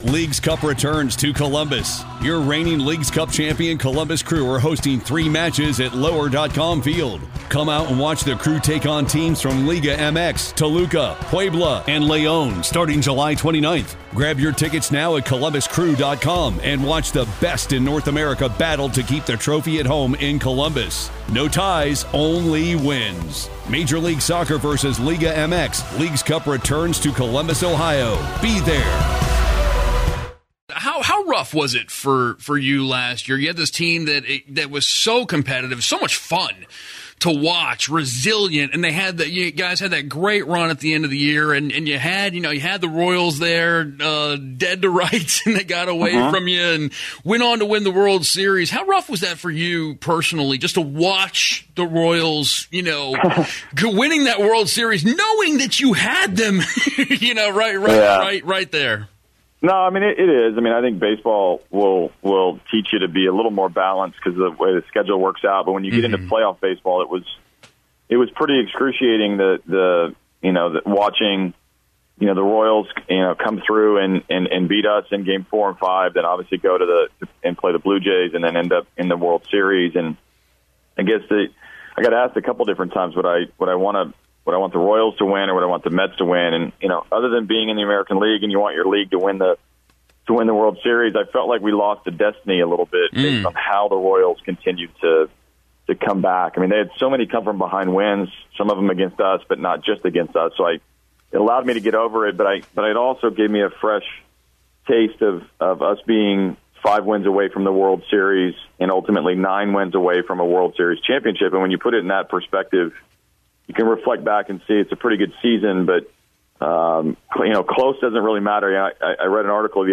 Houston Astros manager A.J. Hinch, joins "Reality Check" to discuss how the team is moving forward from an ALDS defeat to the Kansas City Royals in 2015.